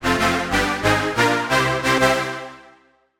Jingle_Win_00
Category 🎮 Gaming
achivement celebrate complete futuristic game gamedev gamedeveloping games sound effect free sound royalty free Gaming